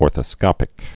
(ôrthə-skŏpĭk)